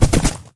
gallop2.mp3